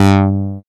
113 CLAV  -L.wav